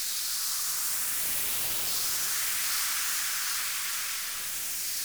steam.ogg